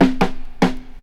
05DR.BREAK.wav